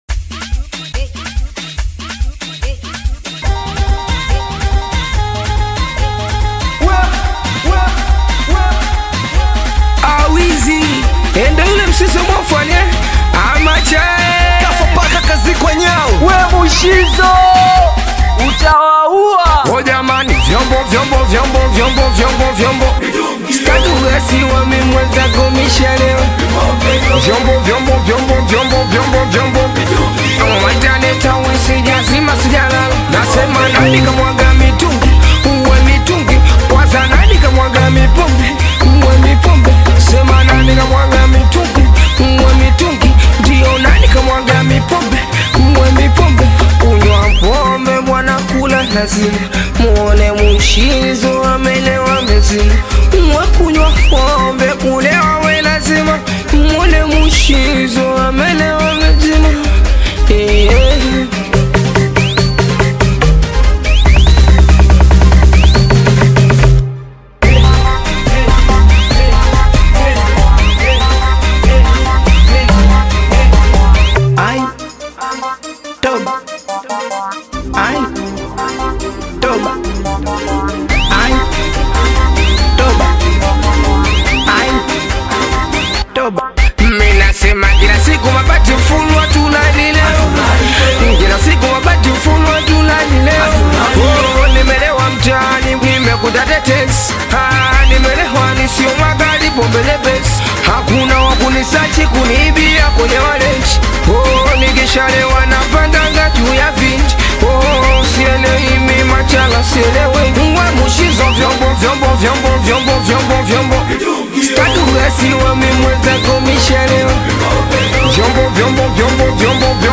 Singeli music track
Singeli You may also like